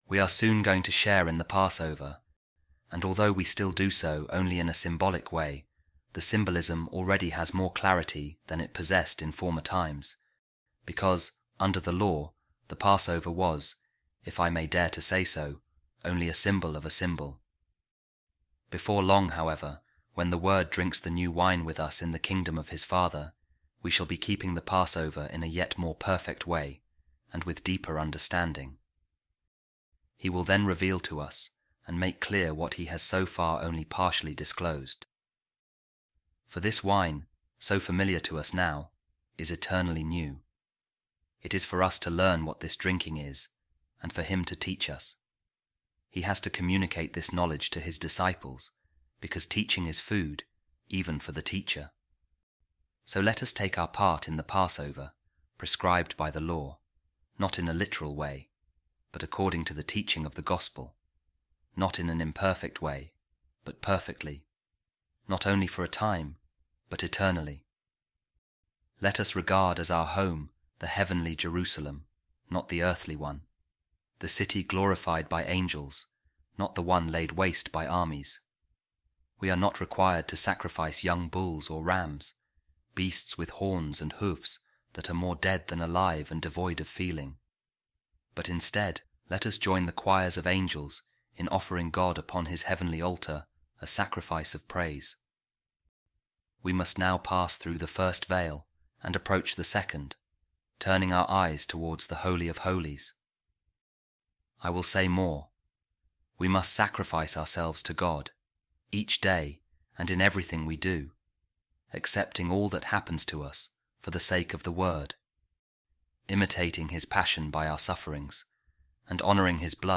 A Reading From The Addresses of Saint Gregory Nazianzen | A Lenten Sermon